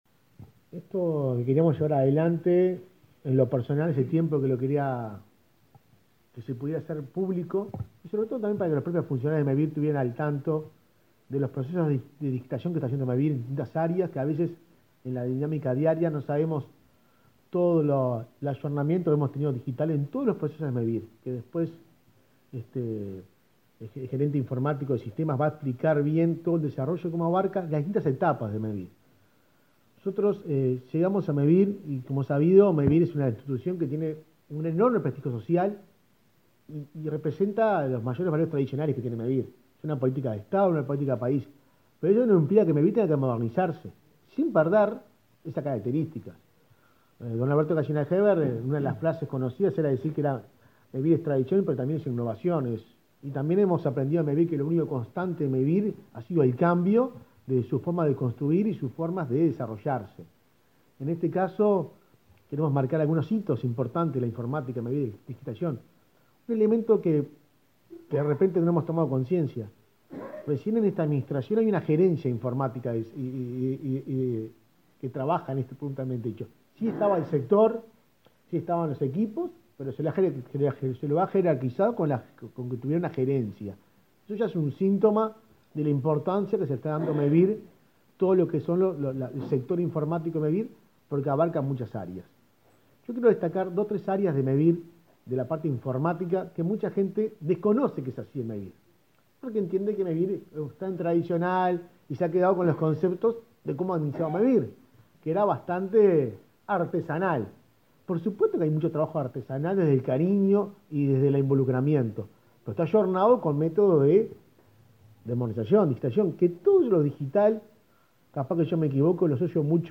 Palabras de autoridades en acto de Mevir
Palabras de autoridades en acto de Mevir 05/10/2023 Compartir Facebook X Copiar enlace WhatsApp LinkedIn Este jueves 5, en el Palacio Legislativo, Mevir presentó el proceso de digitalización institucional del organismo. El presidente de la entidad, Juan Pablo Delgado, y el subsecretario de Vivienda, Tabaré Hackenbruch, destacaron la importancia de este desarrollo en sus distintas etapas.